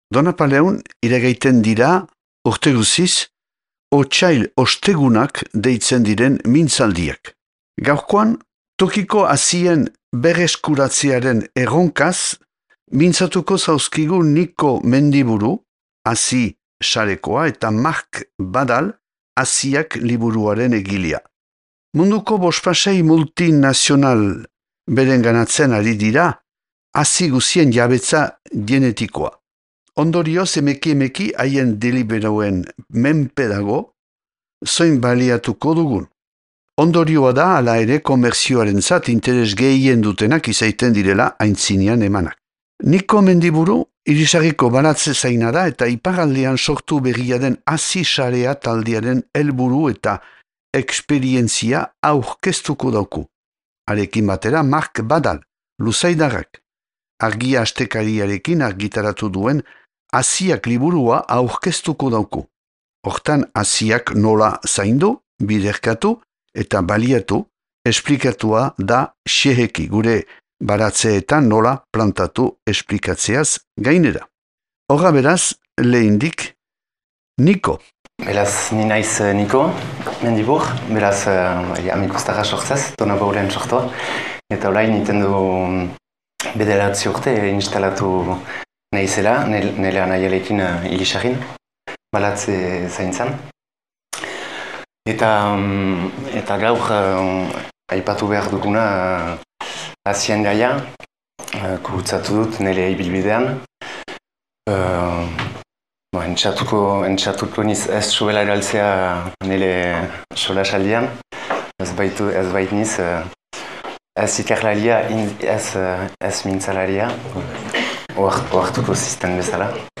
(2025ko otsailaren 13an grabatua Donapaleun.)